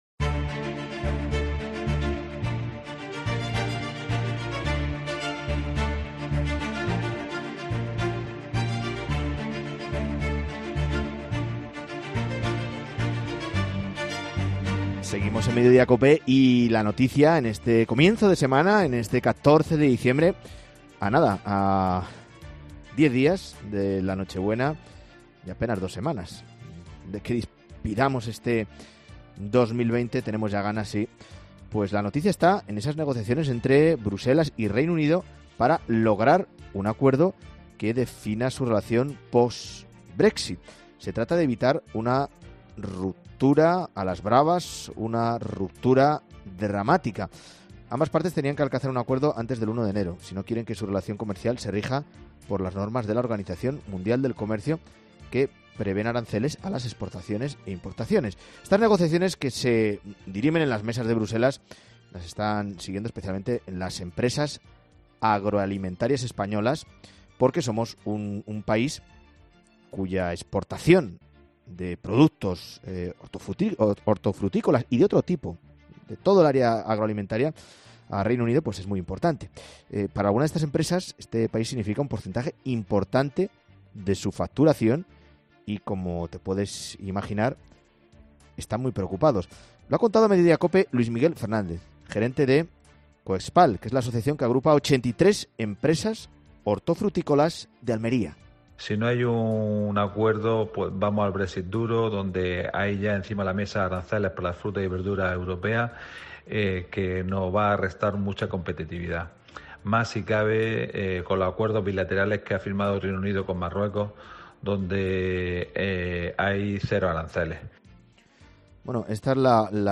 En Mediodía COPE ha asegurado que están siguiendo minuto a minuto el estado de las negociaciones en la prensa y viendo las noticias.